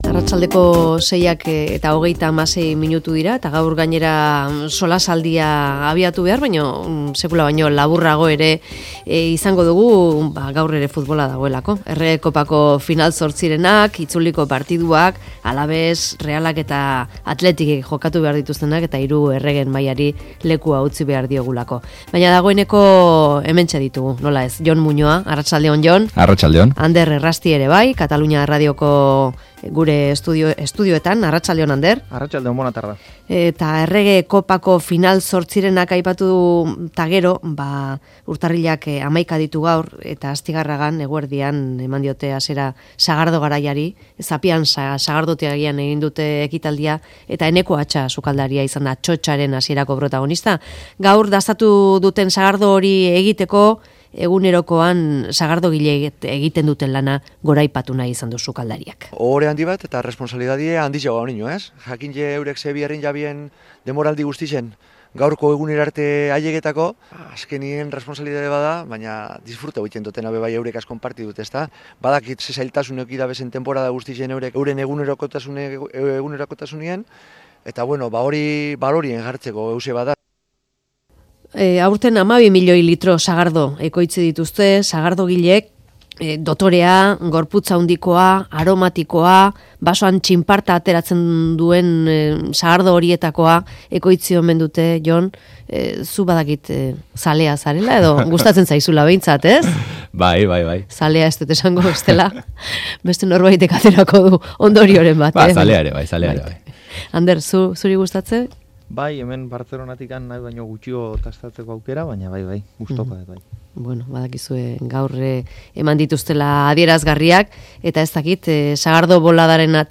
Elizaren baitako sexu abusuak hizpide solasaldian